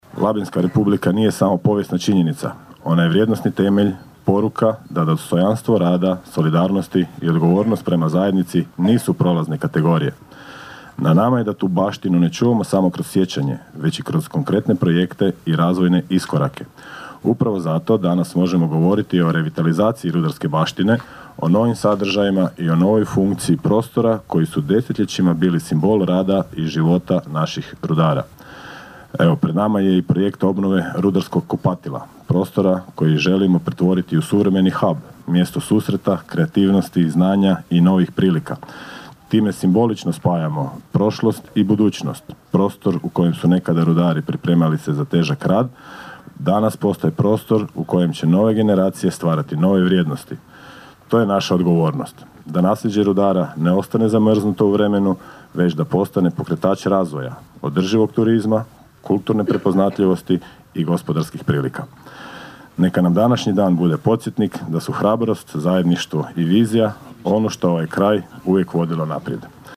Komemoracija na Krvovoj placi na Vinežu
ton – Goran Vlačić 1), zaključio je zamjenik gradonačelnika Goran Vlačić.